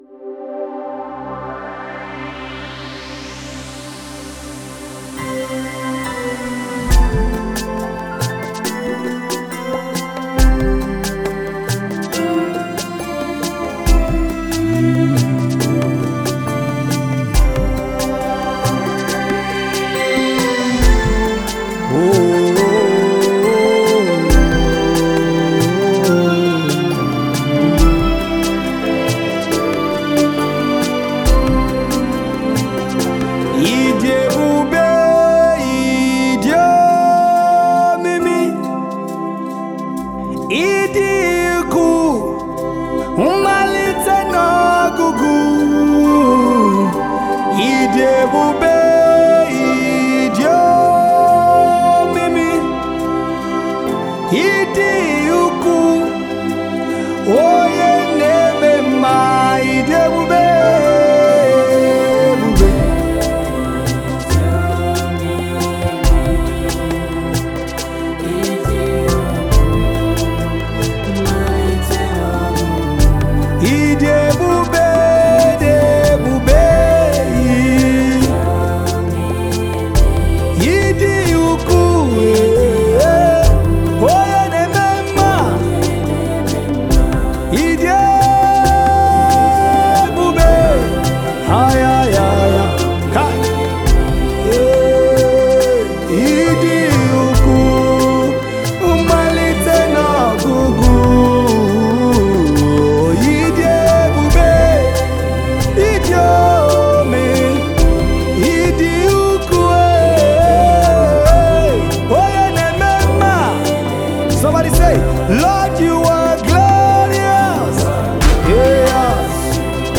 spirit-filled song